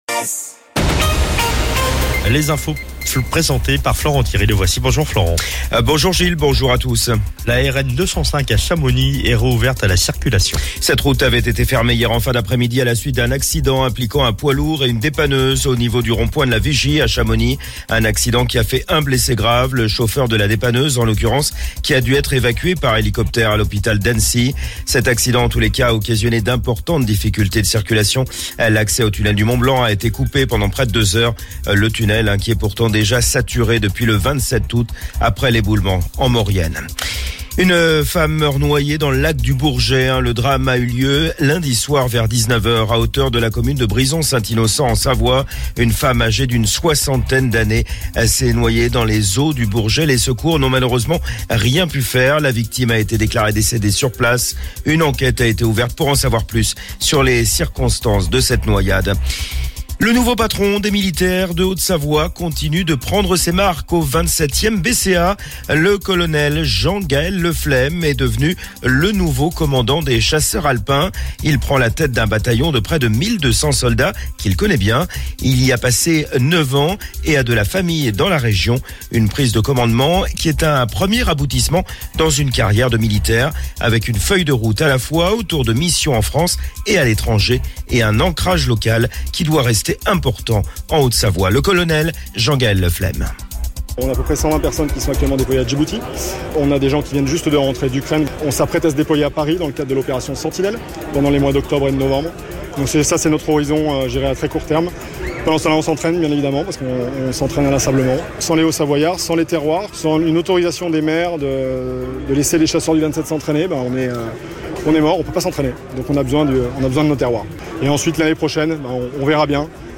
Le Flash Info, le journal d'ODS radio